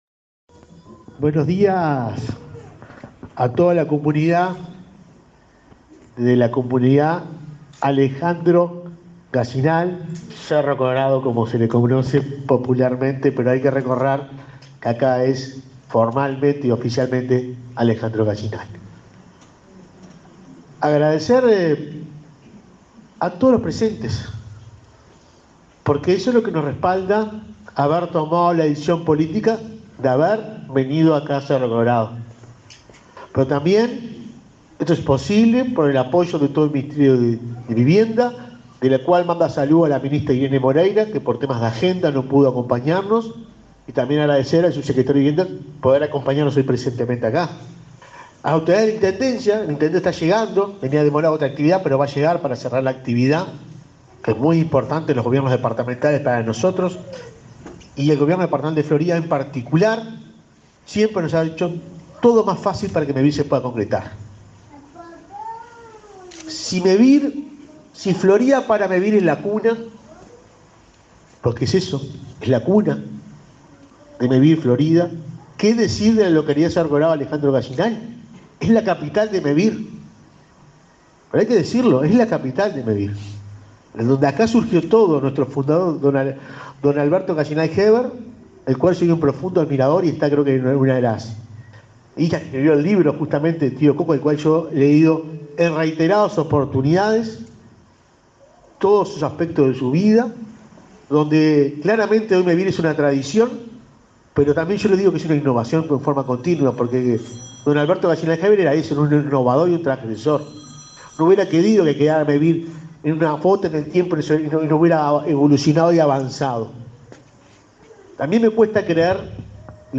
Palabras del presidente de Mevir, Juan Pablo Delgado